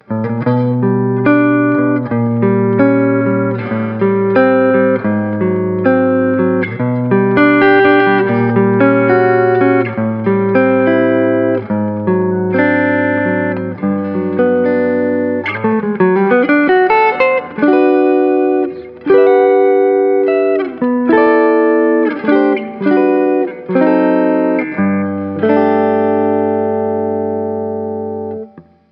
Clean
RAW AUDIO CLIPS ONLY, NO POST-PROCESSING EFFECTS